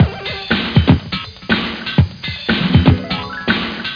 00021_Sound_beatloop.aiff.11.8